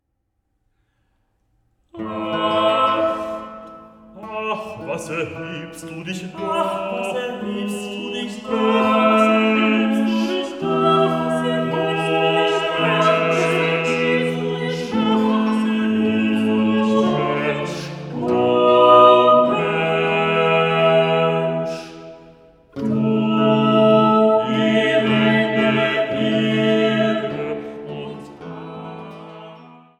Sopran
Tenor
Harfe
Theorbe
Orgel